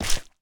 resin_break1.ogg